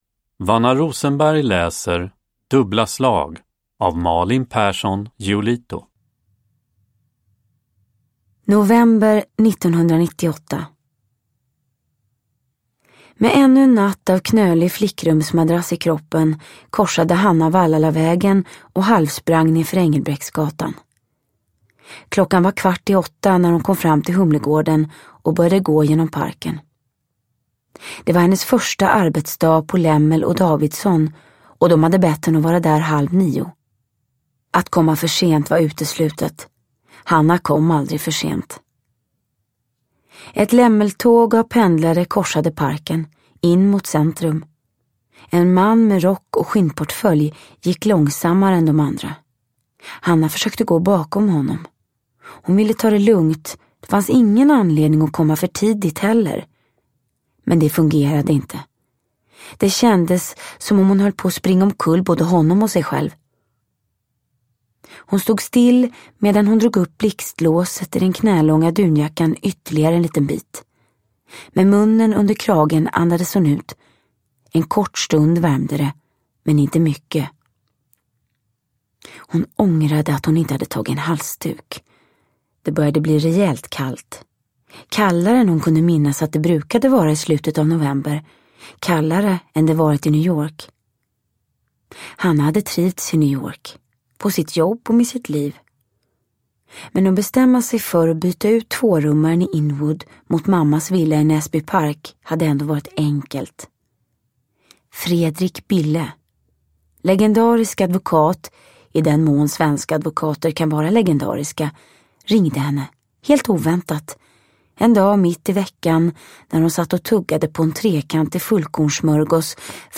Uppläsare: Vanna Rosenberg
Ljudbok